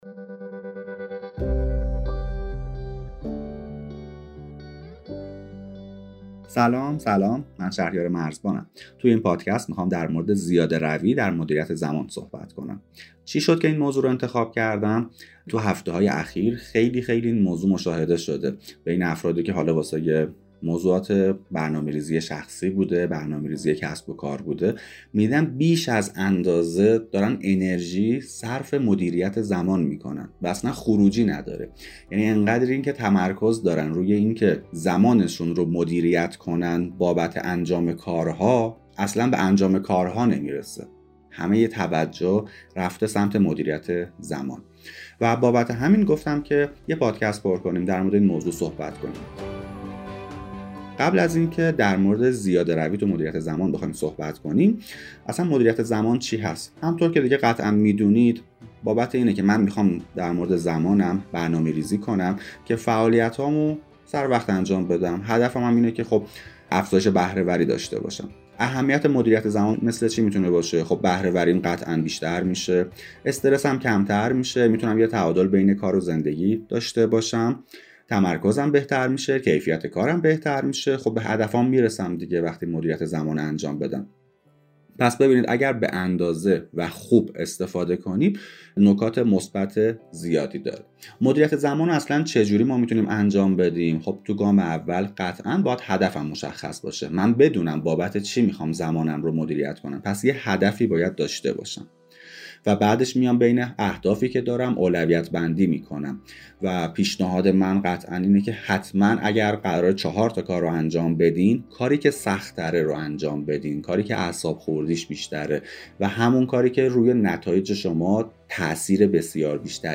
زیاده روی در مدیریت زمان روش های اصولی مدیریت زمان پادکست کسب و کار